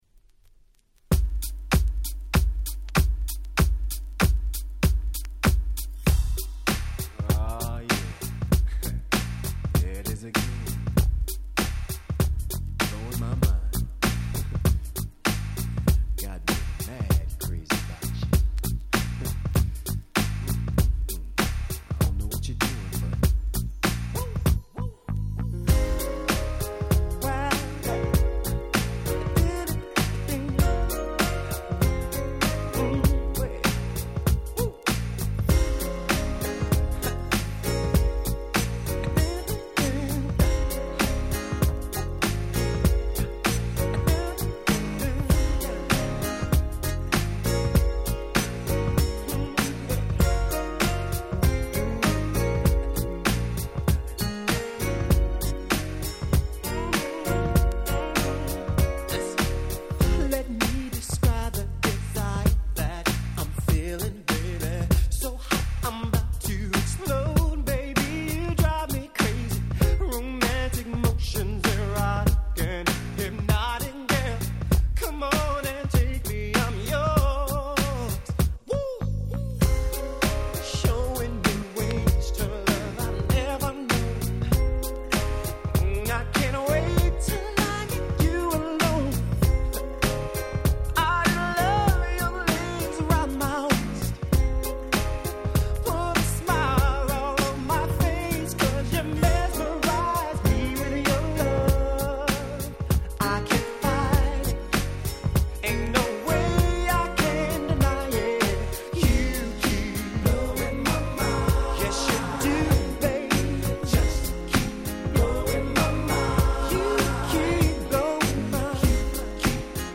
96' Super Nice R&B !!
ズッシリとしたADMビートにDreamin'なメロディーと甘いボーカルが載っためちゃくちゃ良い曲！！